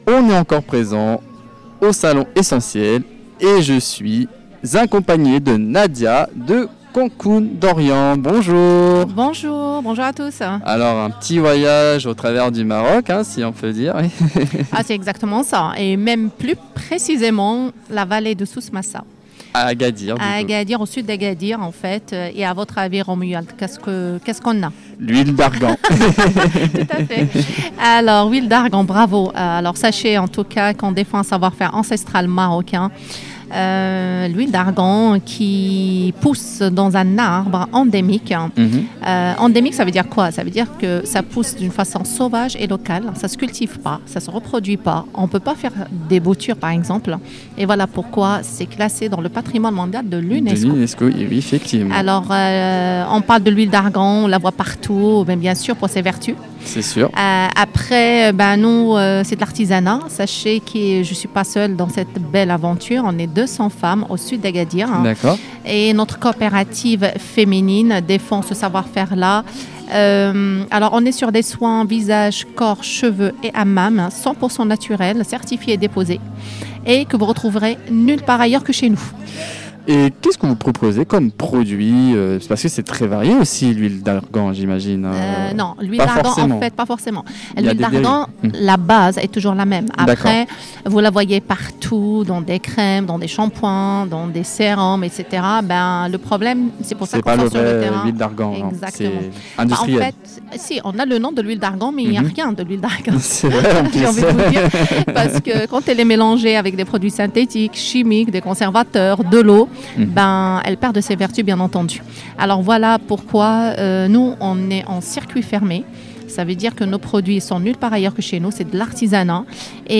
Les interviews du Salon Essenti’Elles – Édition 2026
À l’occasion du Salon Essenti’Elles, organisé les 7 et 8 mars 2026 au gymnase de Châtillon-Coligny, l’équipe de Studio 45 est allée à la rencontre des organisatrices et des nombreux exposants présents durant ce week-end consacré au bien-être et à l’univers féminin.